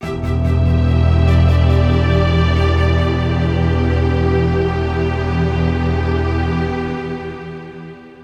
Ráfaga musical. Intriga.
intriga
melodía
ráfaga
Sonidos: Música